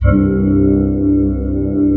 Index of /90_sSampleCDs/Best Service Dream Experience/SYN-PAD